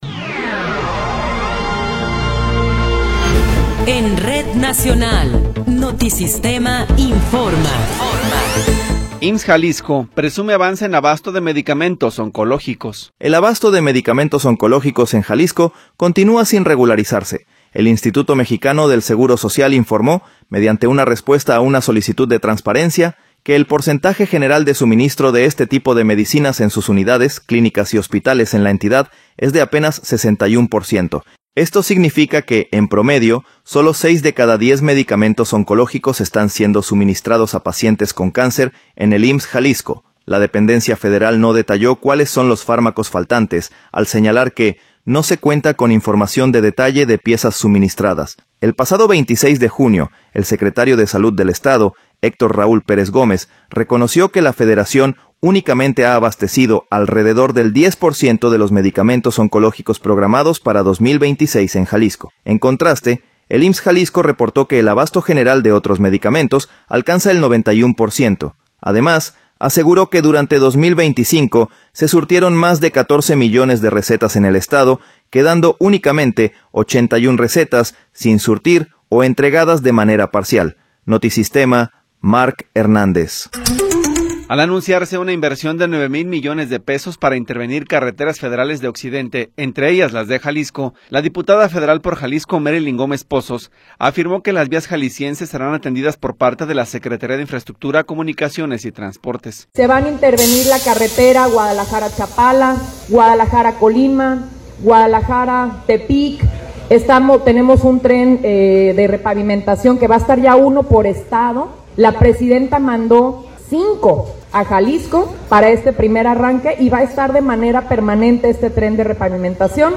Noticiero 15 hrs. – 30 de Enero de 2026
Resumen informativo Notisistema, la mejor y más completa información cada hora en la hora.